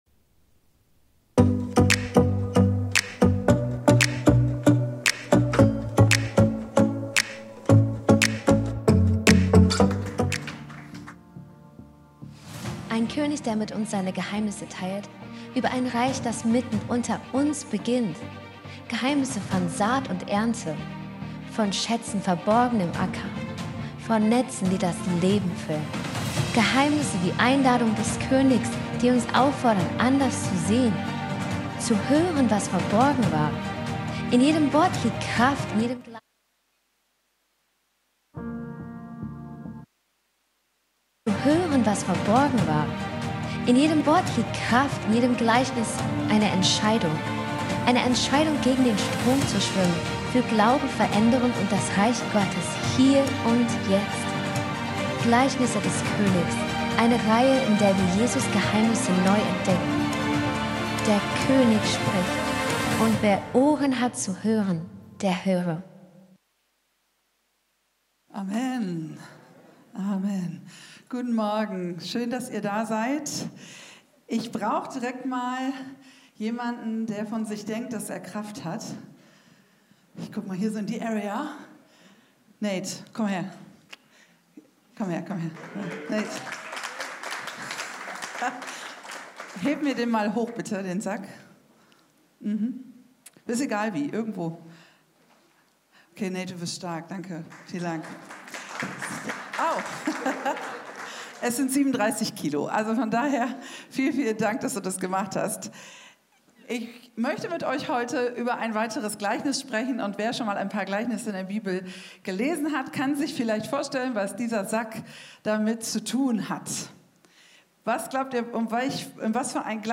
Video und MP3 Predigten
Kategorie: Sonntaggottesdienst Predigtserie: Die Gleichnisse des Königs